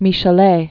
(mēsh-ə-lā, mēsh-lā), Jules 1798-1874.